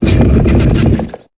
Cri de Crabaraque dans Pokémon Épée et Bouclier.